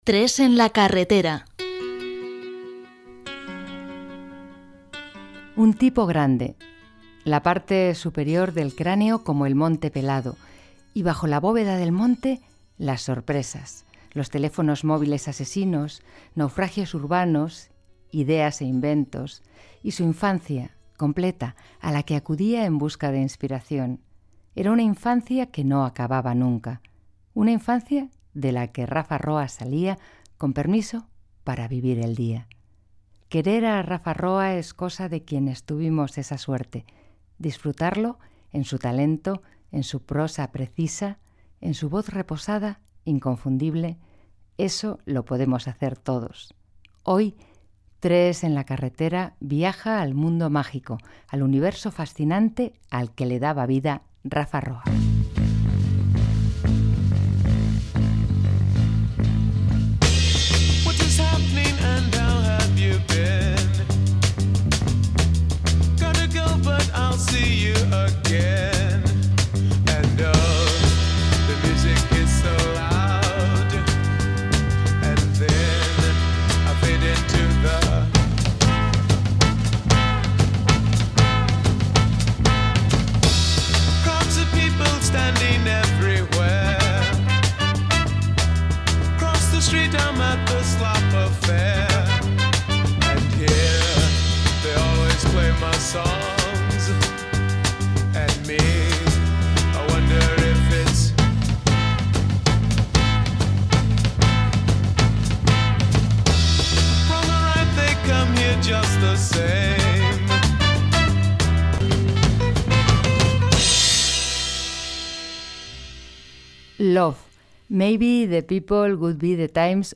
Indicatiu del programa, presentació, tema musical, narració "Un viaje al aeropuerto"
FM